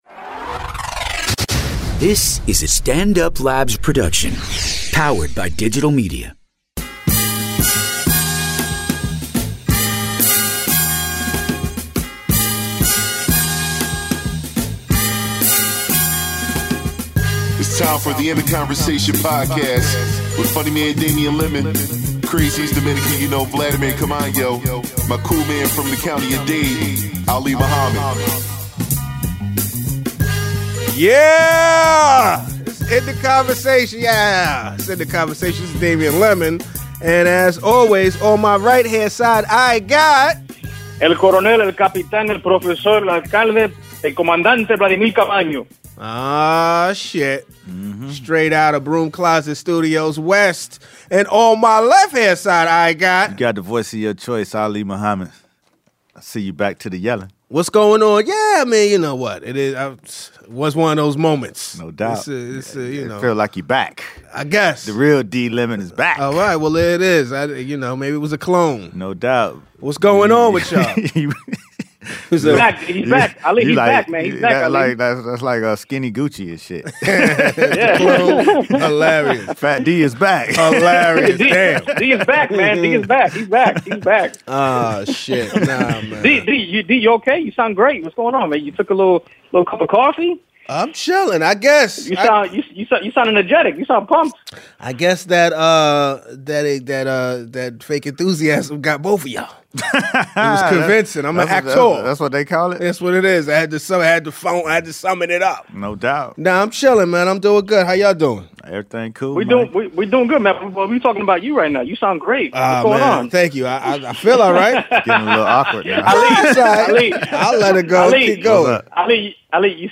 On this week's ITC, the guys sit down to get in the conversation about the continued opposition to the Trump agenda, the idea of resistance fatigue, New Edition's effect on the culture, the Super Bowl, and more.